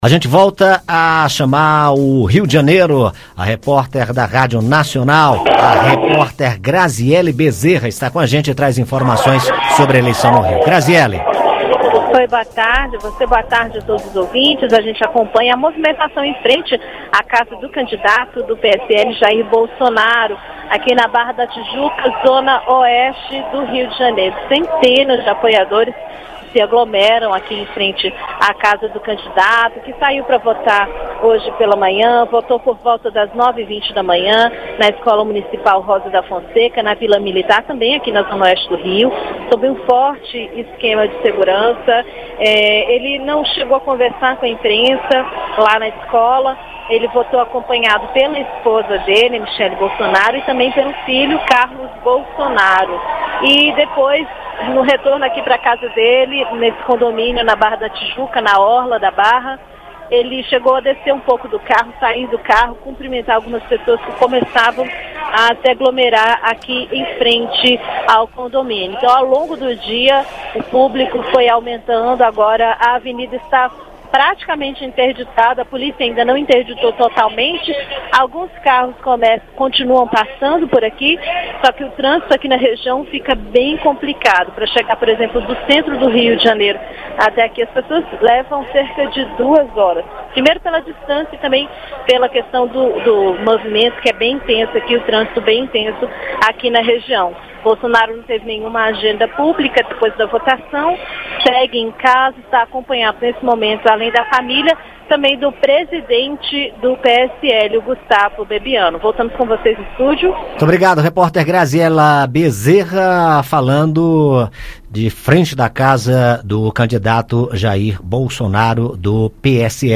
O candidato do PSL, Jair Bolsonaro, vai esperar a apuração dos resultados em casa, ao lado de familiares e do presidente do partido dele, Gustavo Bebiano. Na região onde ele mora, na Barra da Tijuca, no Rio de Janeiro, centenas de simpatizantes já lotam as ruas e a avenida em frente à casa dele está praticamente interditada. A repórter